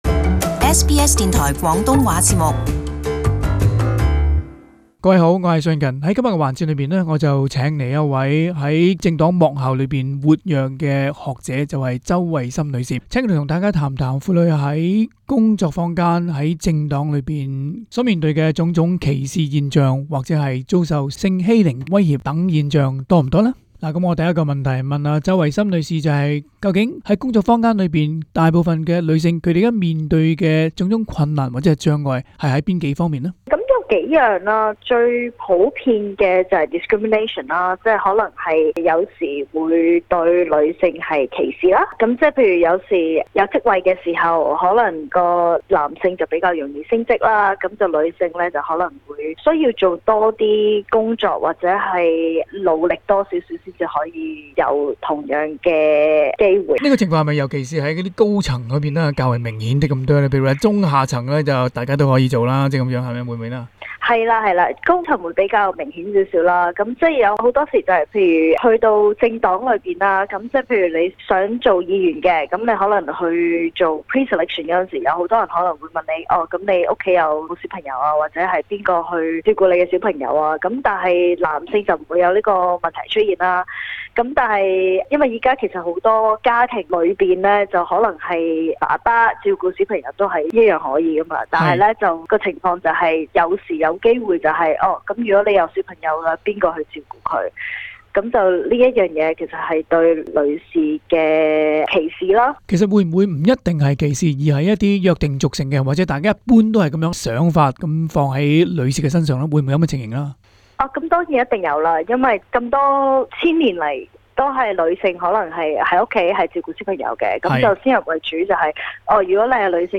SBS Source: SBS SBS廣東話節目 View Podcast Series Follow and Subscribe Apple Podcasts YouTube Spotify Download (17.87MB) Download the SBS Audio app Available on iOS and Android 在澳洲政界及商界，在職婦女們面對的問題比男同事多。